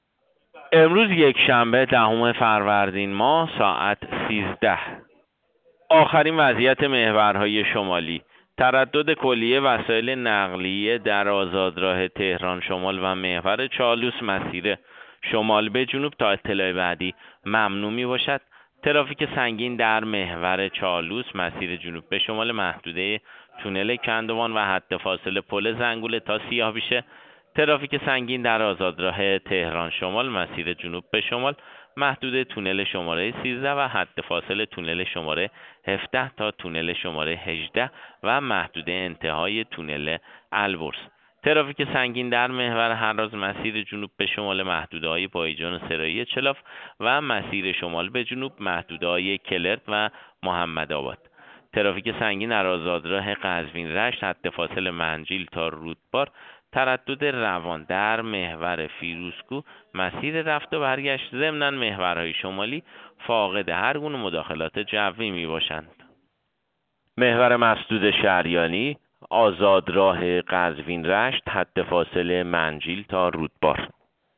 گزارش رادیو اینترنتی از آخرین وضعیت ترافیکی جاده‌ها ساعت ۱۳ دهم فروردین؛